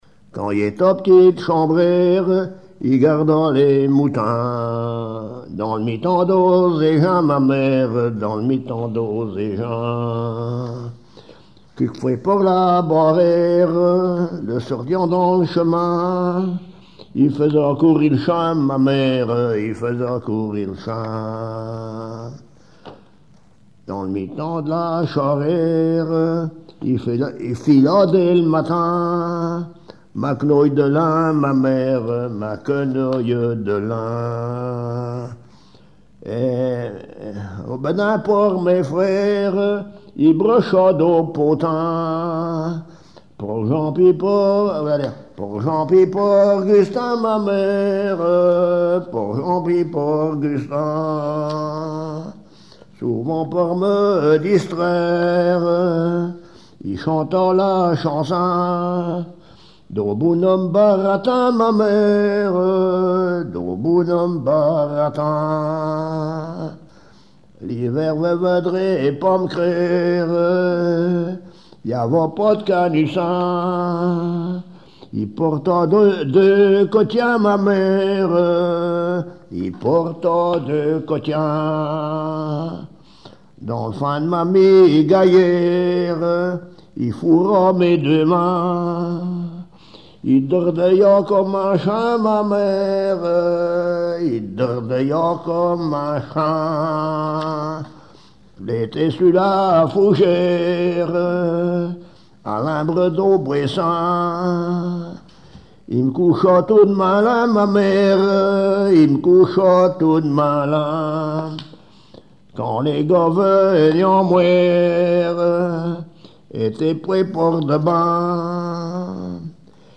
chanteur(s), chant, chanson, chansonnette
Pièce musicale inédite